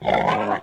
tusker-hit.ogg